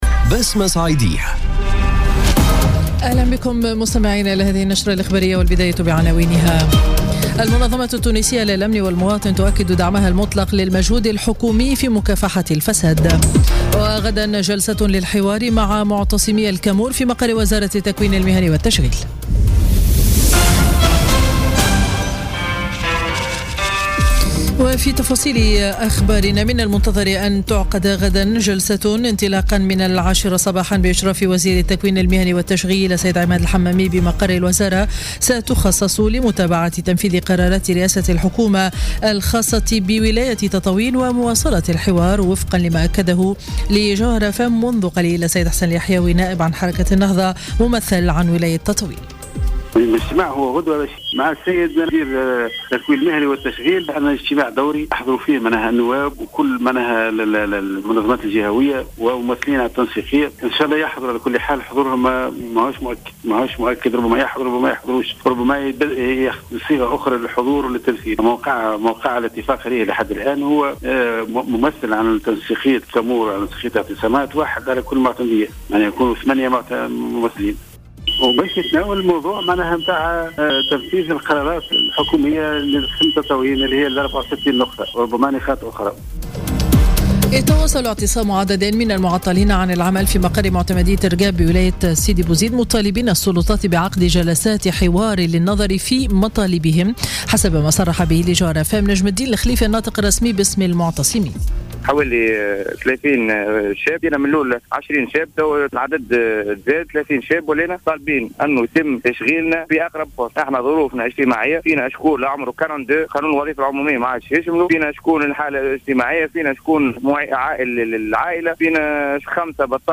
نشرة أخبار منتصف النهار ليوم الثلاثاء 30 ماي 2017